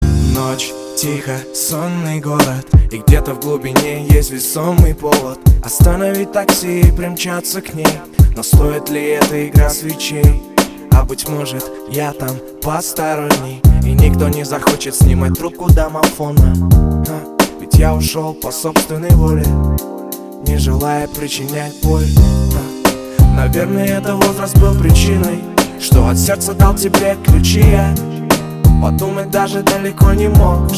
• Качество: 320, Stereo
мужской вокал
грустные
спокойные
романтичные